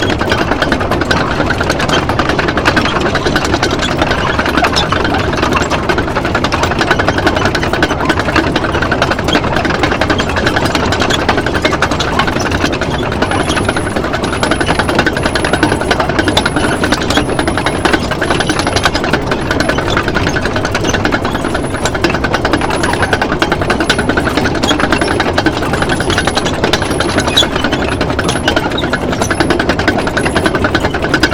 tank-tracks-2.ogg